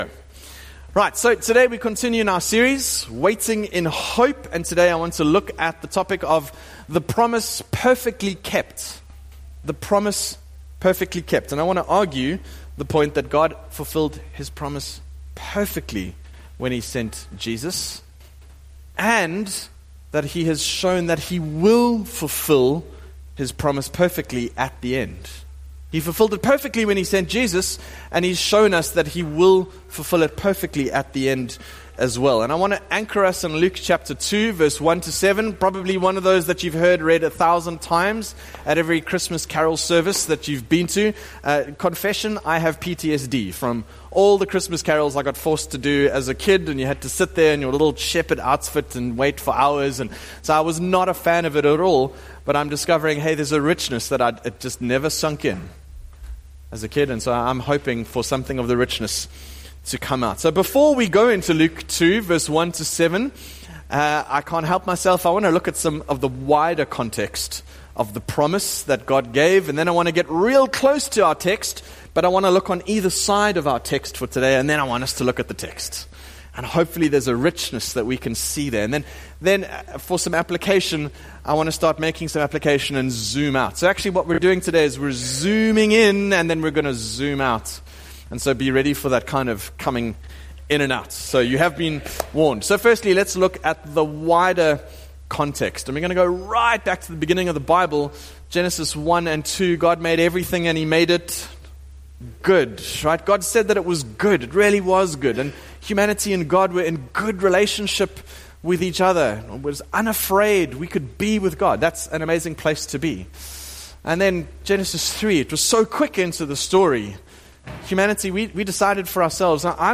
SERMON: The Promise Perfectly Kept
Advent Guide 2025 Evening Service